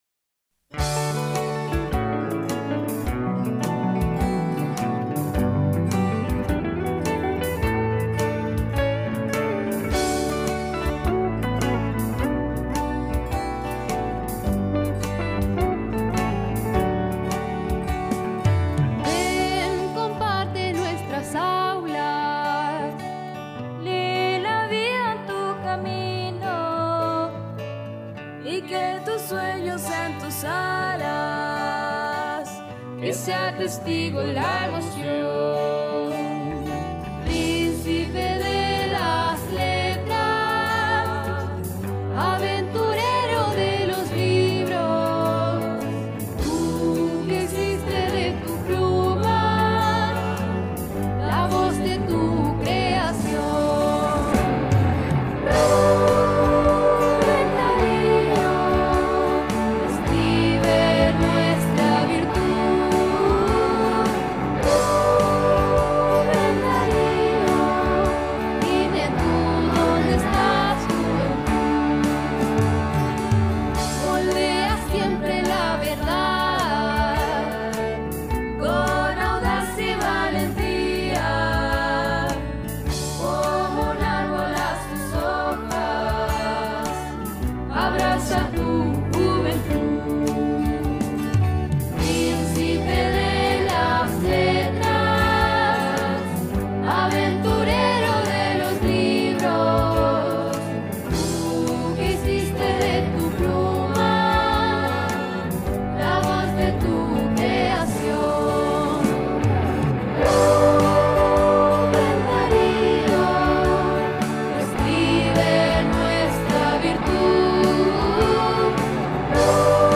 La interpretación estuvo a cargo de un talentoso grupo de estudiantes de distintos niveles, quienes con dedicación y emoción dieron vida a esta obra: